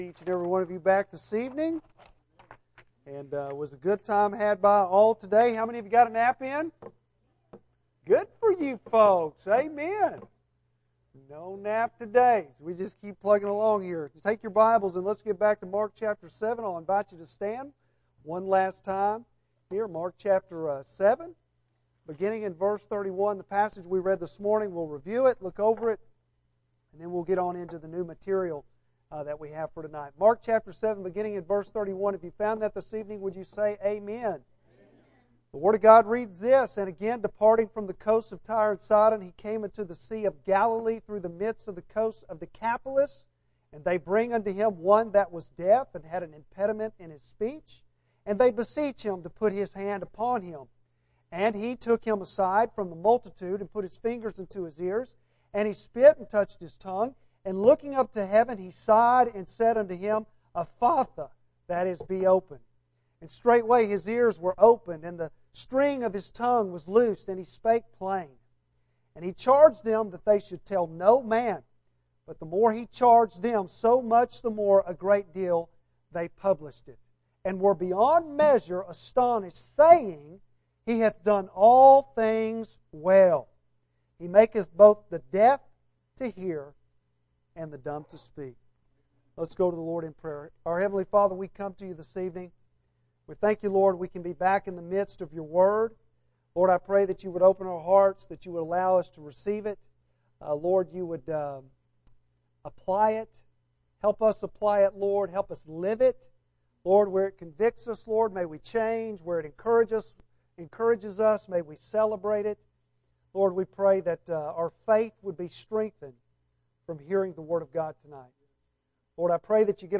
Bible Text: Mark 7:31-37 | Preacher